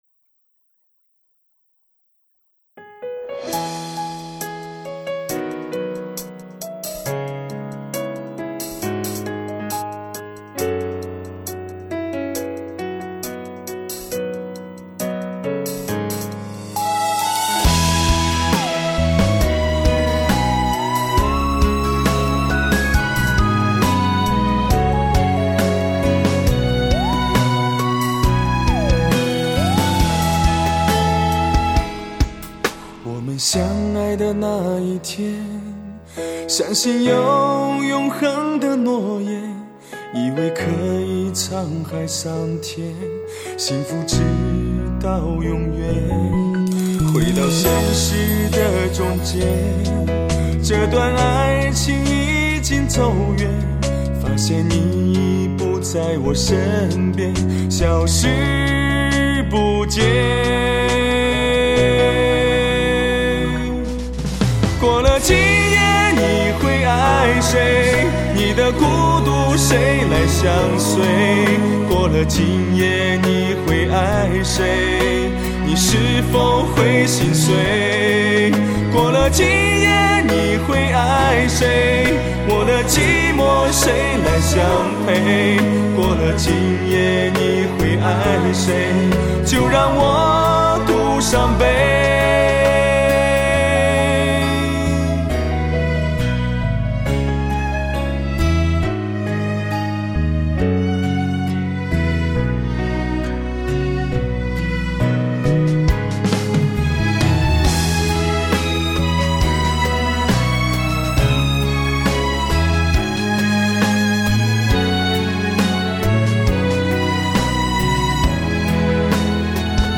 楼主的链接失效了，网上找到这首歌试听一下，好象不怎么样，唱得很白的感觉。